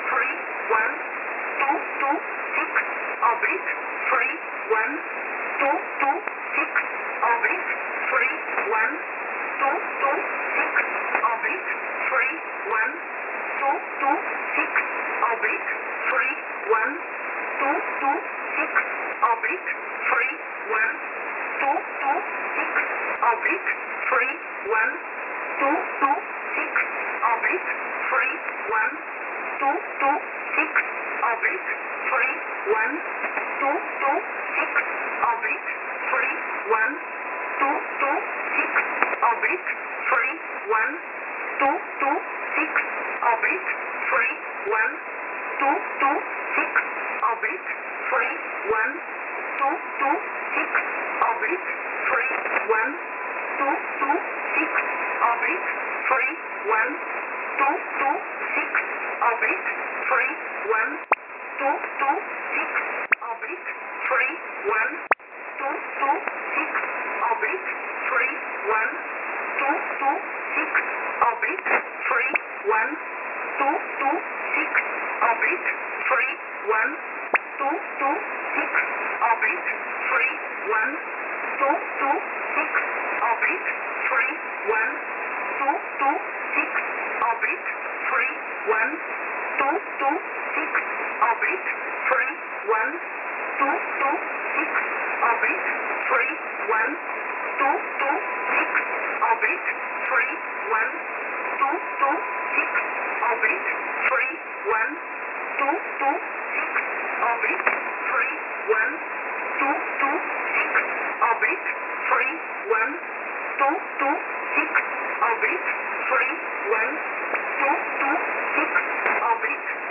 Mode: USB
Recording: websdr_recording_2015-02-08T15-49-26Z_15632.0kHz.wav Date (mm/dd/yy): 02/08/15